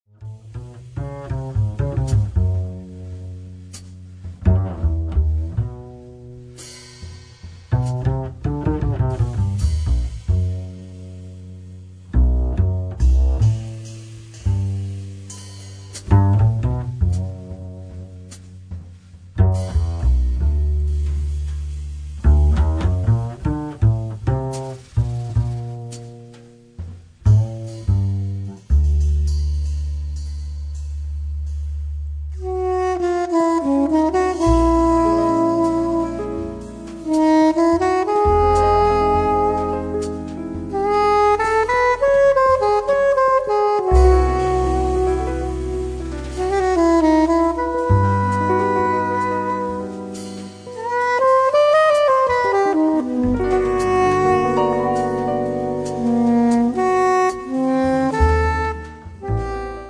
alto e soprano sax
Classic and electric guitars
piano, fender rhodes, organ
bass
drums